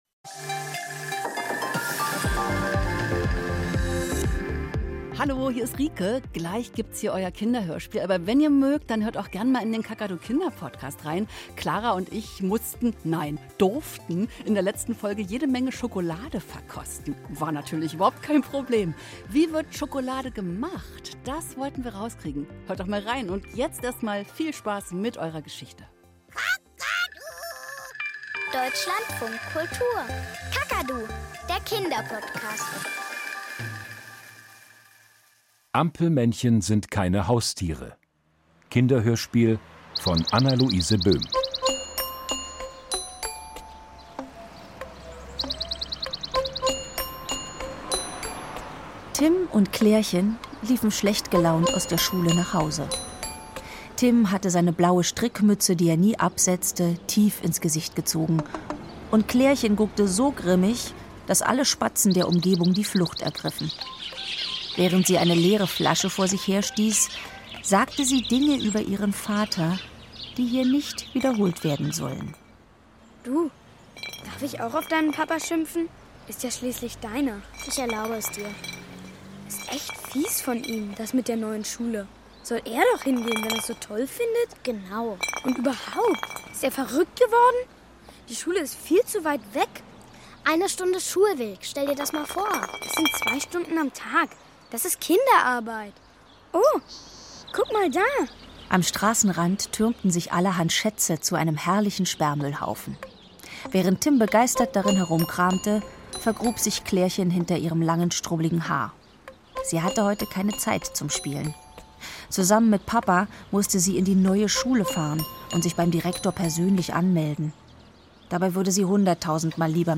Kinderhörspiel - Ampelmännchen sind keine Haustiere